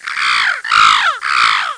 1 channel
PTAK1.mp3